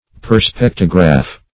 Definition of perspectograph.
Search Result for " perspectograph" : The Collaborative International Dictionary of English v.0.48: Perspectograph \Per*spec"to*graph\ (p[~e]r*sp[e^]k"t[-o]*gr[.a]f), n. [L. perspectus (p. p. of perspicere to look through) + -graph.]